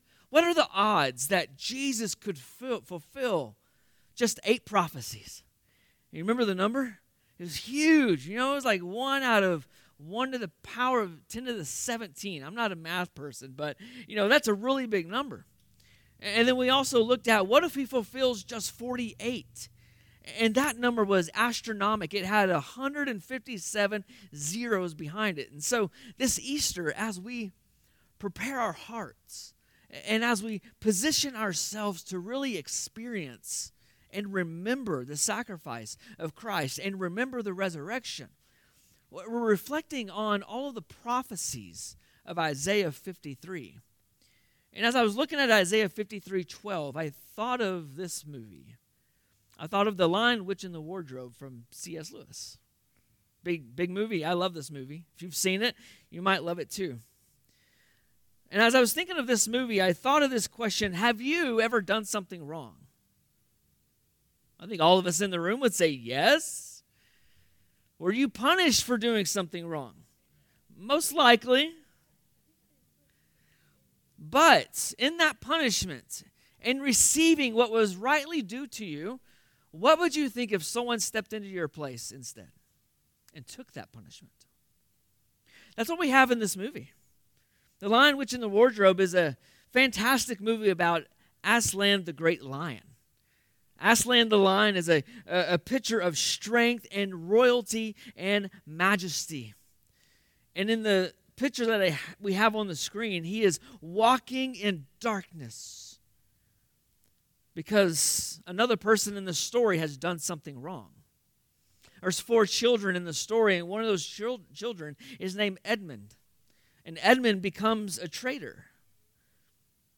Sermons | Gainesville Bible Church